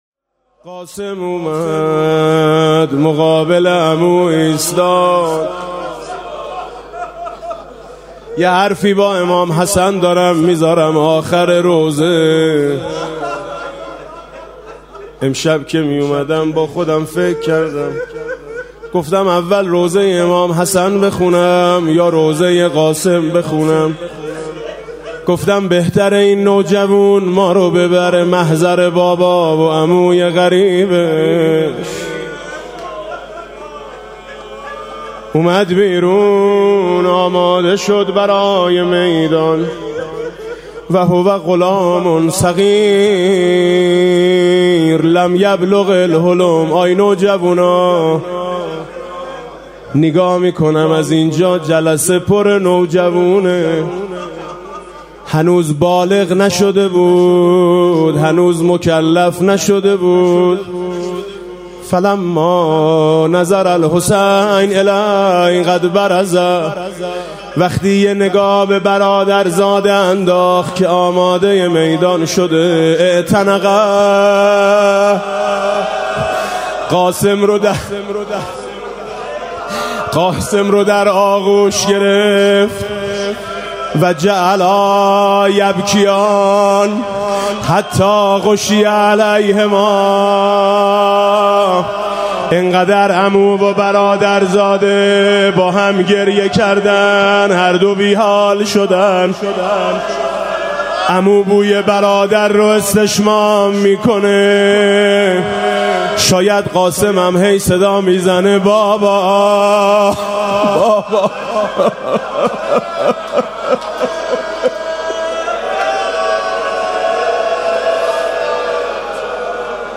مداحی میثم مطیعی برای شهید حاجی زاده در محرم
محفل عزاداری شب ششم محرم هیأت آیین حسینی با سخنرانی حجت‌الاسلام رفیعی و بانوای میثم مطیعی در امامزاده قاضی الصابر (علیه‌السلام) برگزار شد.
روضه
نوحه
شور